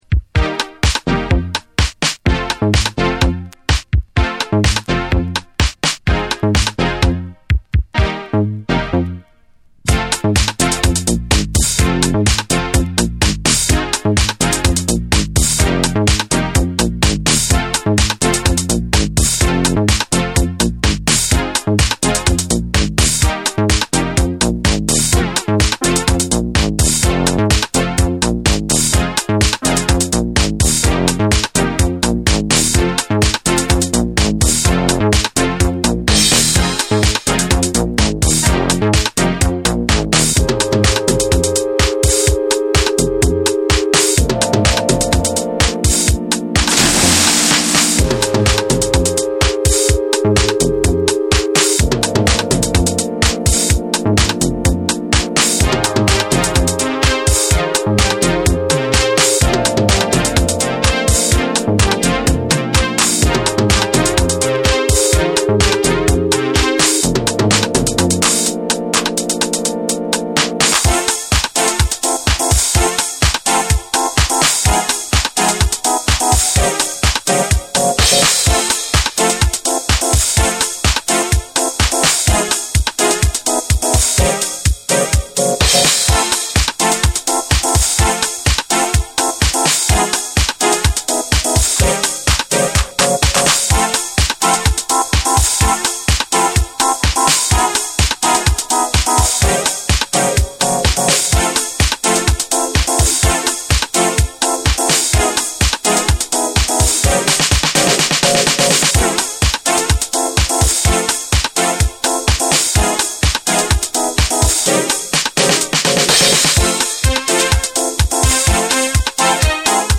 ソウルフルでエフェクティブな女性ヴォーカルをフィーチャーし、躍動感あふれるエレクトリックなディープハウスを展開！
TECHNO & HOUSE